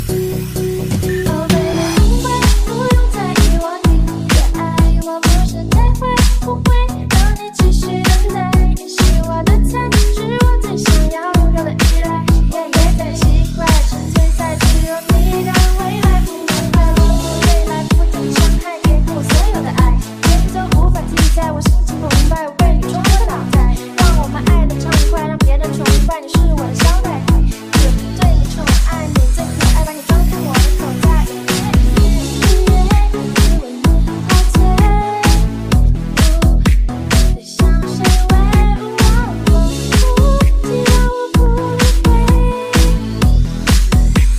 Thể loại nhạc chuông: Nhạc Tik Tok